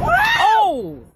scream_1.wav